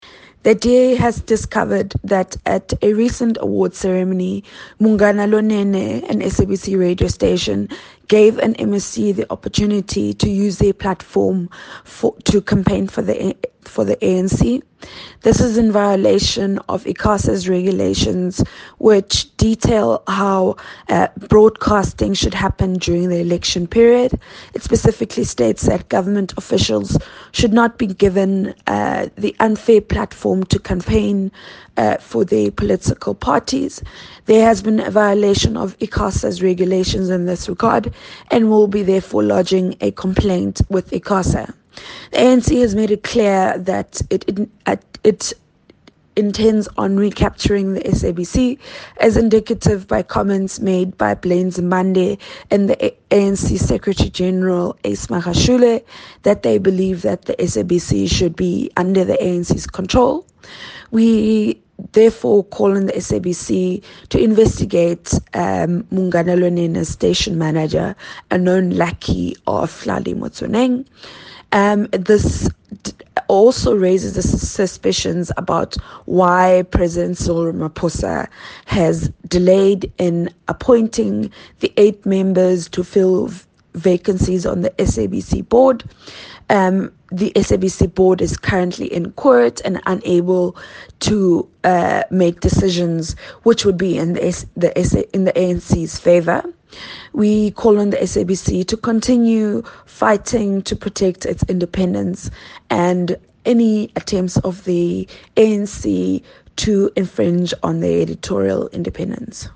soundbite from Phumzile Van Damme, the DA Shadow Minister of Communication.